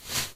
clothes3.ogg